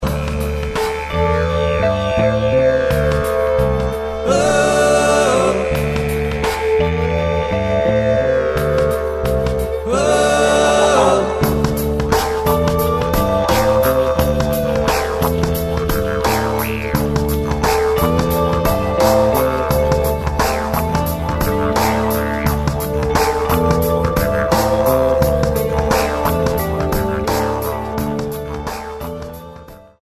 hip hop beat